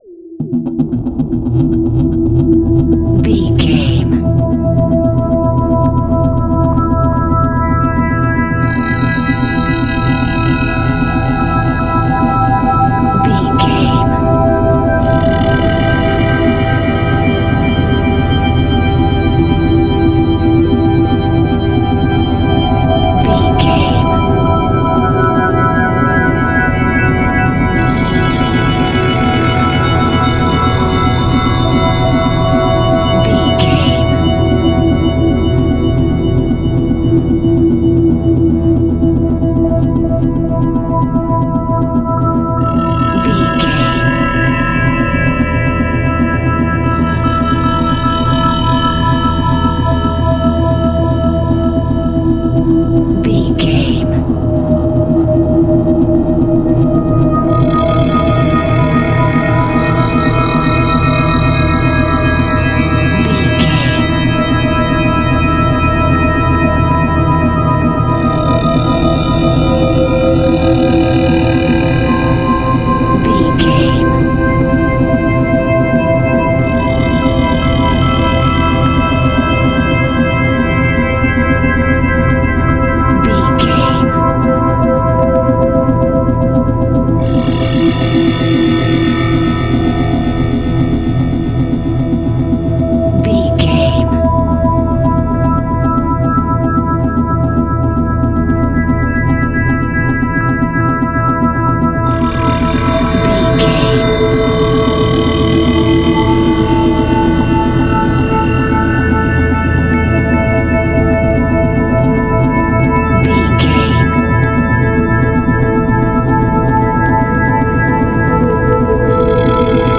Aeolian/Minor
scary
tension
ominous
dark
suspense
eerie
synthesizer
keyboards
ambience
pads